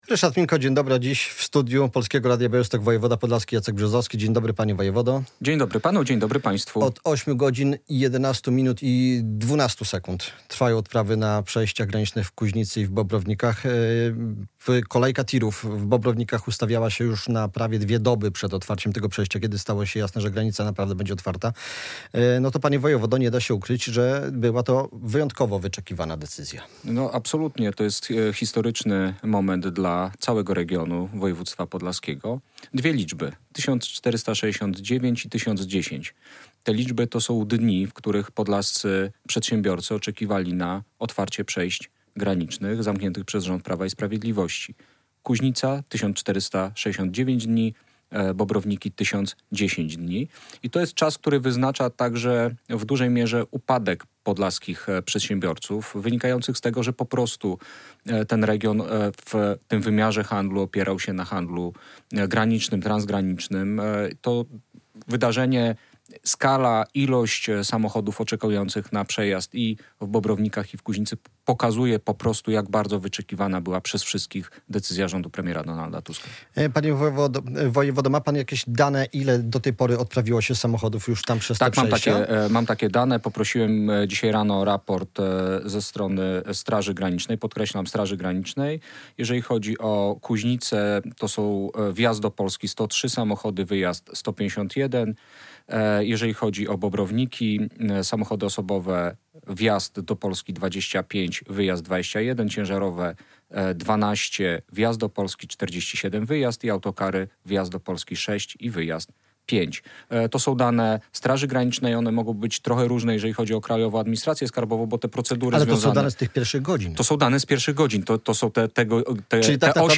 - To jest historyczny moment dla całego regionu województwa podlaskiego - mówił w Polskim Radiu Białystok o ponownym otwarciu przejść granicznych z Białorusią w Kuźnicy i Bobrownikach Jacek Brzozowski.
Radio Białystok | Gość | Jacek Brzozowski [wideo] - wojewoda podlaski